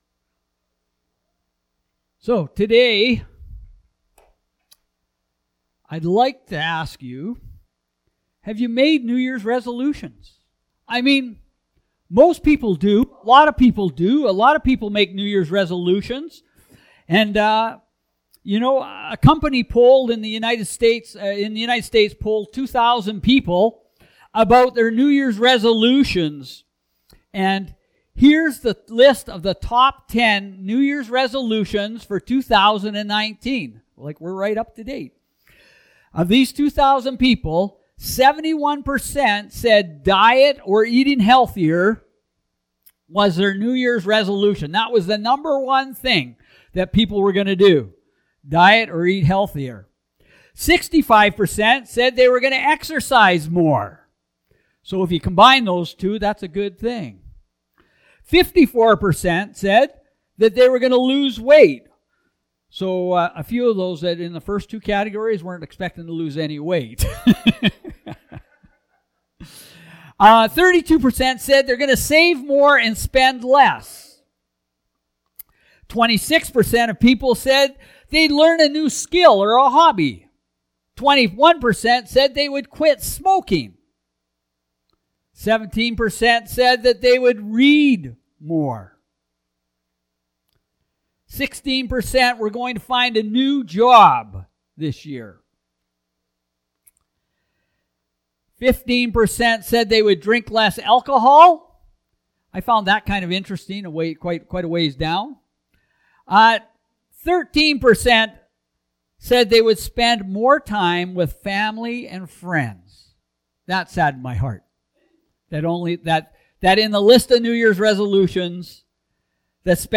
Sermons | Cross Roads Pentecostal Assembly